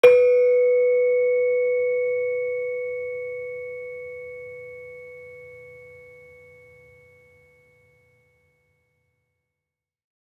HSS-Gamelan-1
Saron-3-B3-f.wav